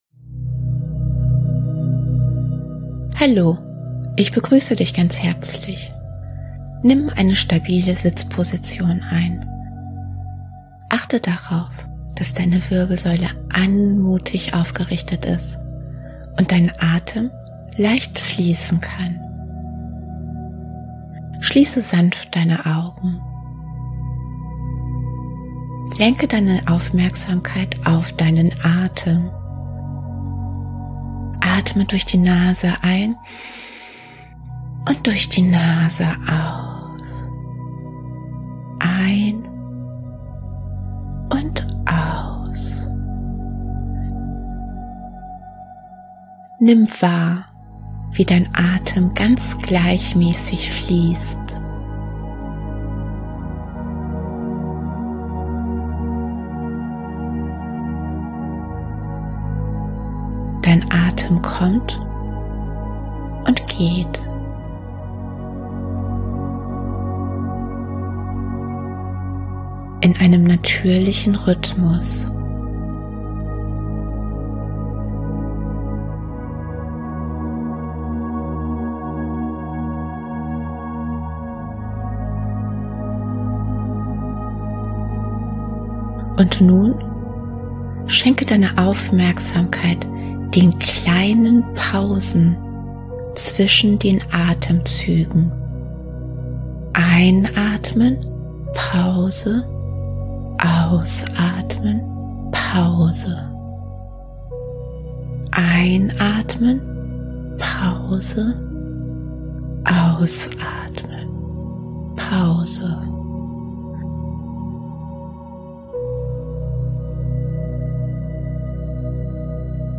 kurze Achtsamkeitsübung
Traumreisen & geführte Meditationen